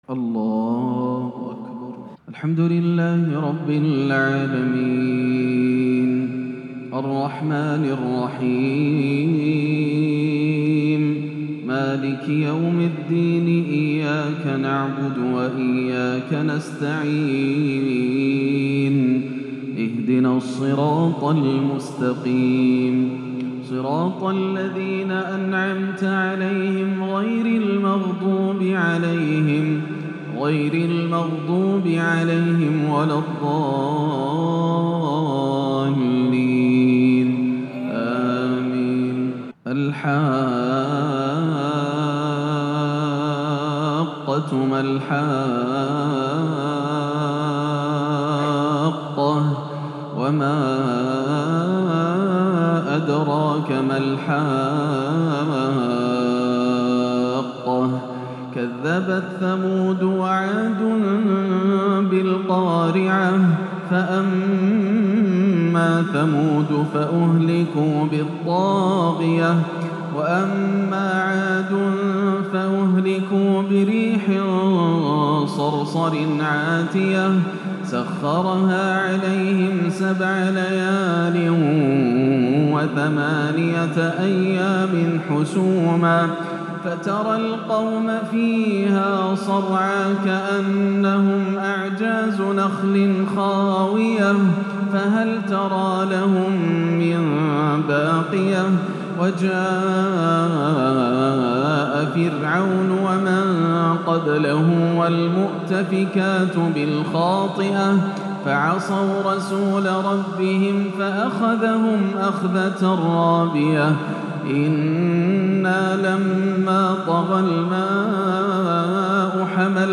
(ثم الجحيم صلّوه) تلاوة خاشعة من سورة الحاقة - عشاء الأحد 2-6-1439هـ > عام 1439 > الفروض - تلاوات ياسر الدوسري